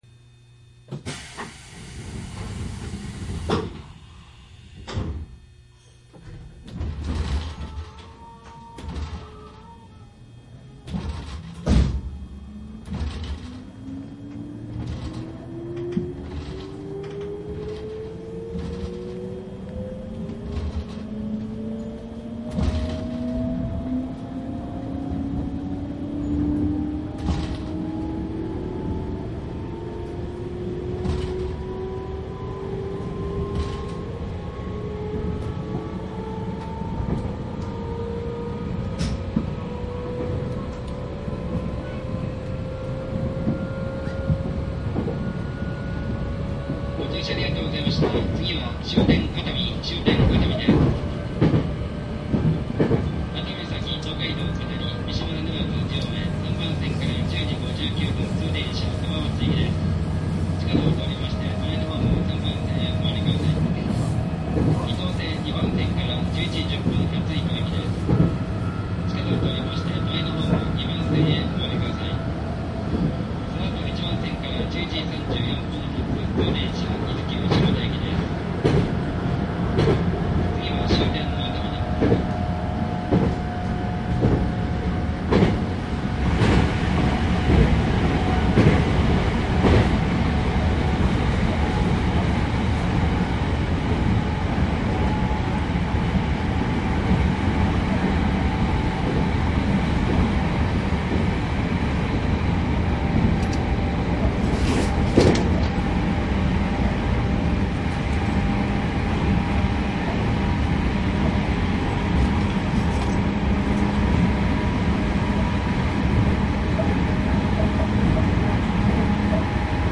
アクティ-215系・サンライズ285系 JR東海道線 鉄道走行音CD
音的には動力車が前後2両づつの配置なので加速時に独特の衝撃音が聞こえます。
（収録日：2000-1-7（日）・クモハ215-103 界磁添加励磁制御 ）
サンプル音声 次は熱海  .mp3
※収録機材は、ソニーDATと収録マイクソニーECM959を使用しております。